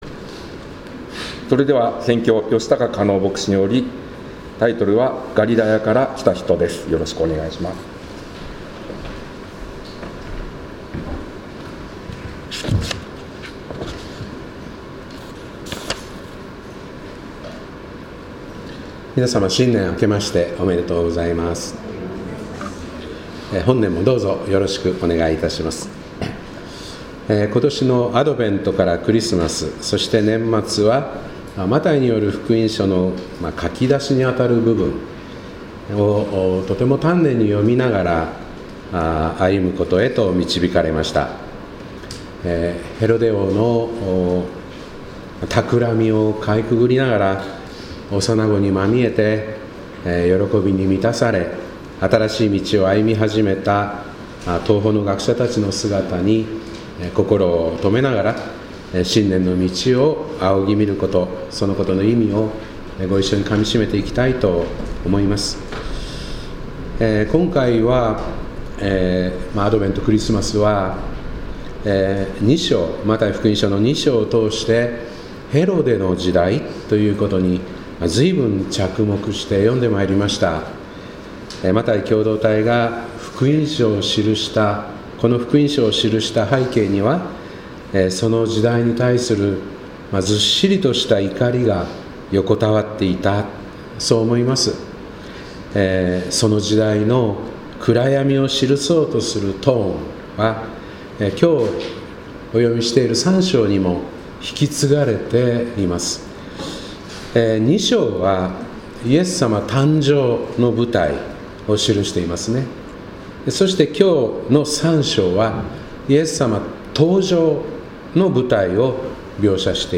2025年1月5日新年礼拝「ガリラヤから来た人」